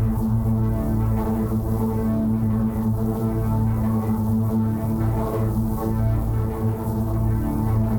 Index of /musicradar/dystopian-drone-samples/Tempo Loops/90bpm
DD_TempoDroneC_90-A.wav